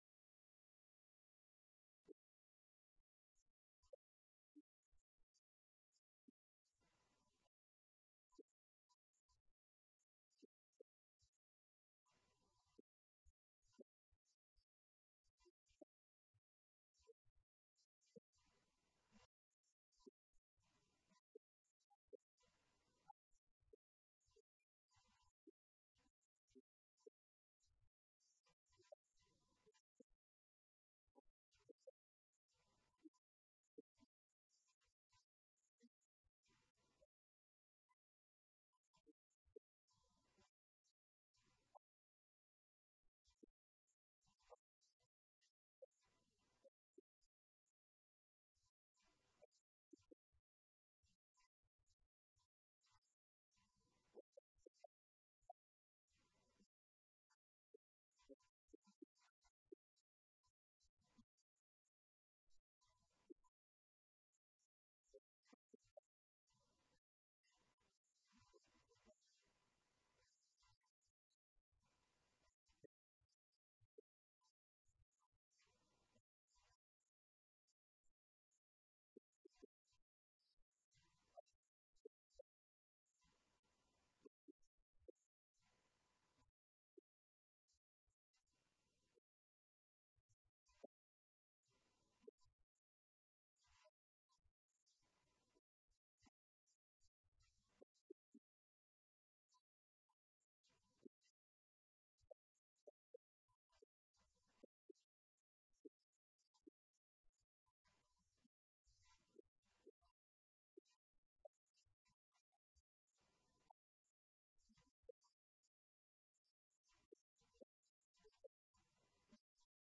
Event: 2019 Men's Development Conference